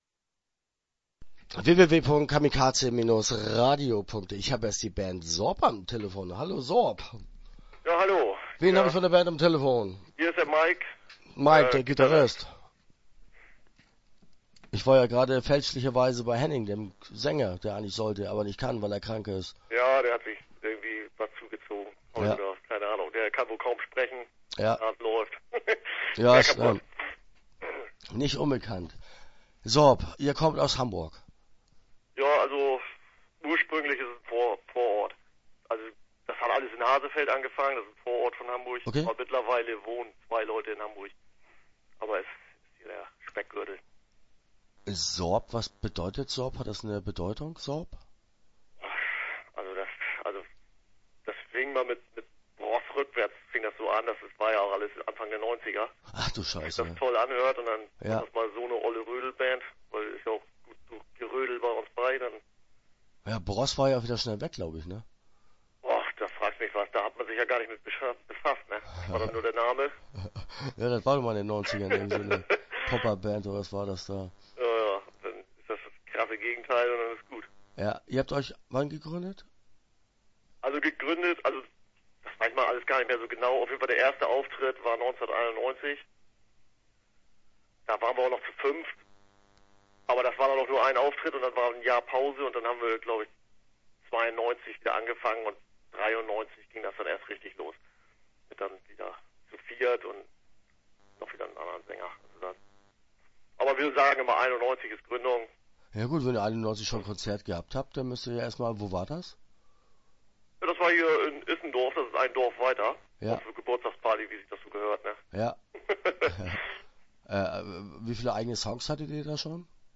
SORB - Interview Teil 1 (10:55)